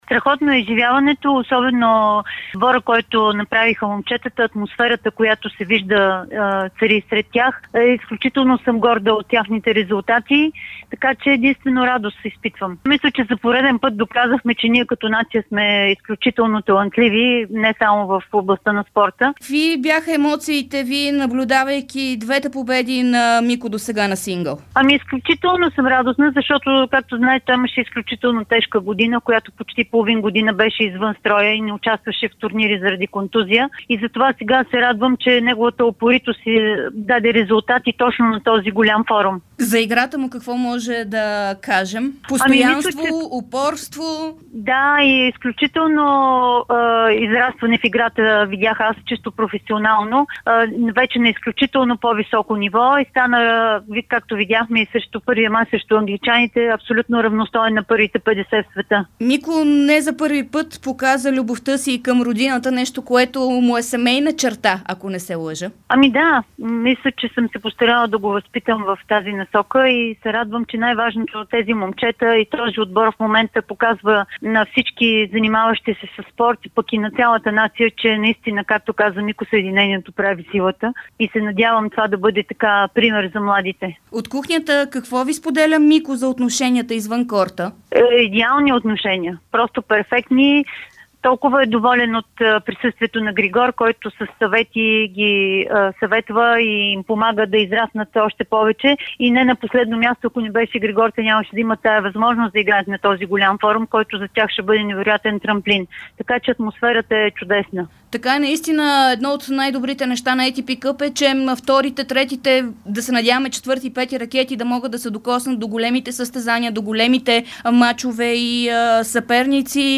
Чуйте я в радиото